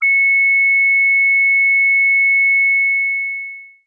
battle_itemApollon_flash.mp3